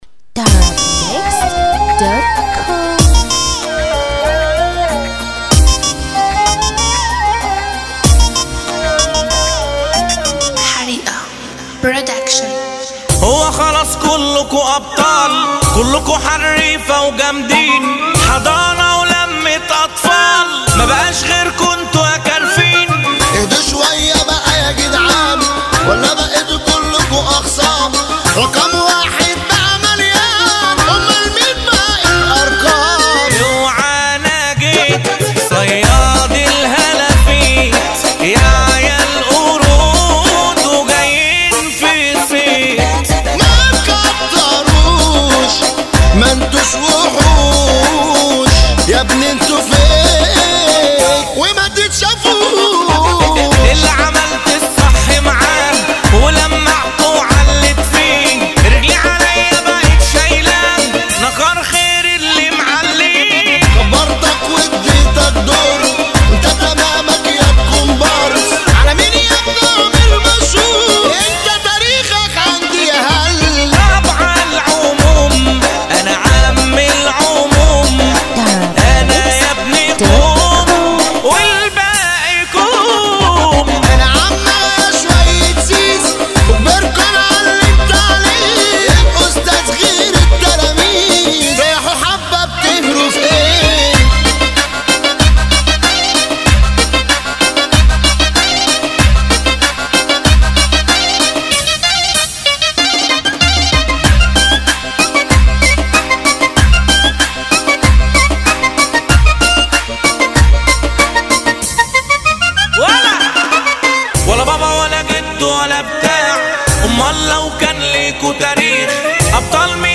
• النوع : shobeiat